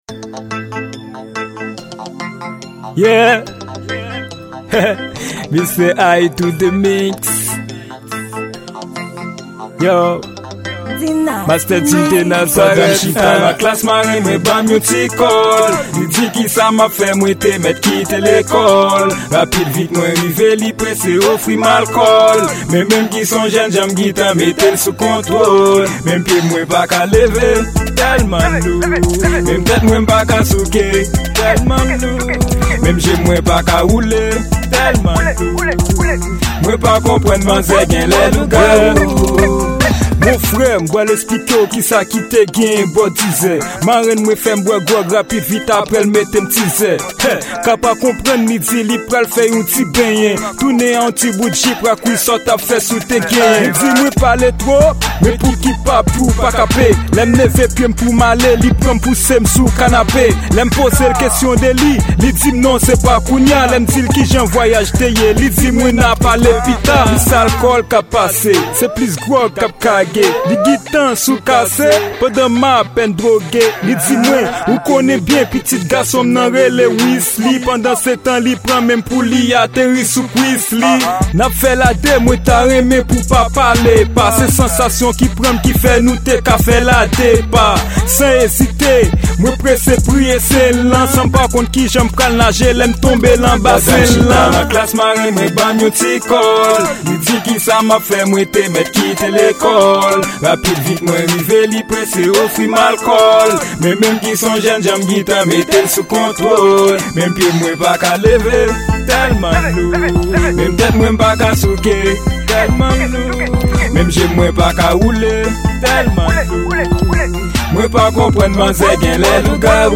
Genre: Rap